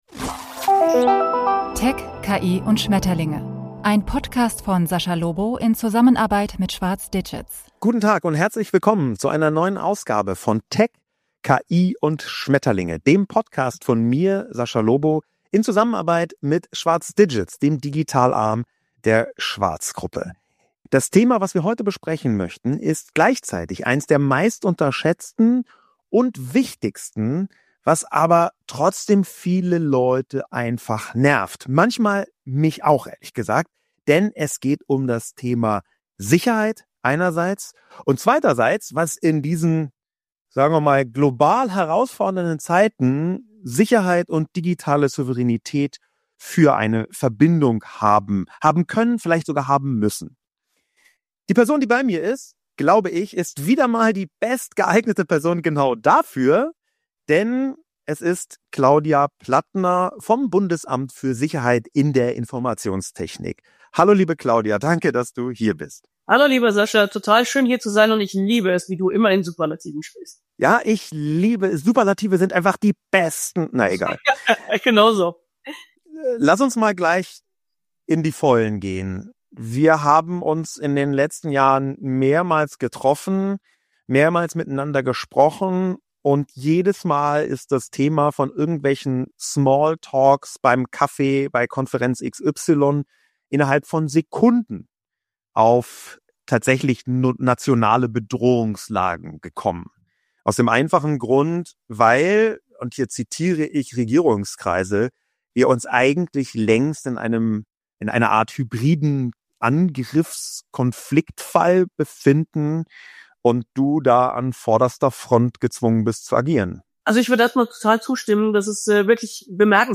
Cybersicherheit ist heute Staatsaufgabe. Sascha Lobo spricht mit BSI-Präsidentin Claudia Plattner über Cyberkrieg, digitale Souveränität, unsichtbare Prävention und die wachsenden Angriffsflächen unserer vernetzten Welt.